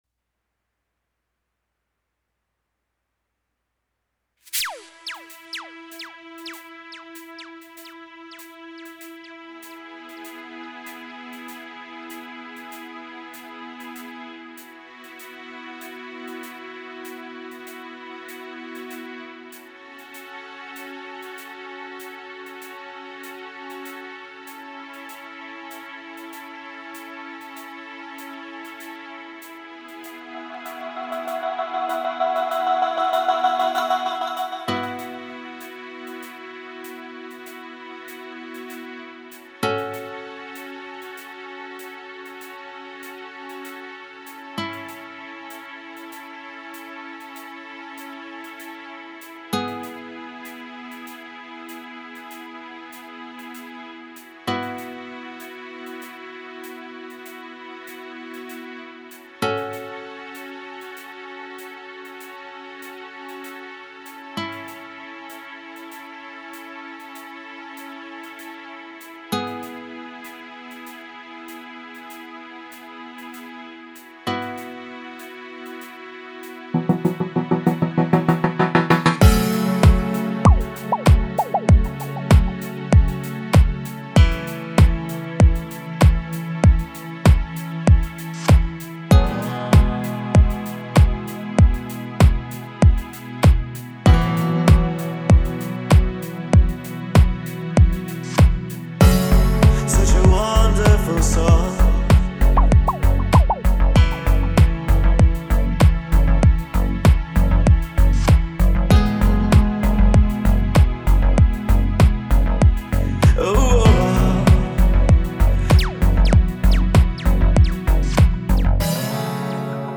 Поп-гитарный-медляк. Sun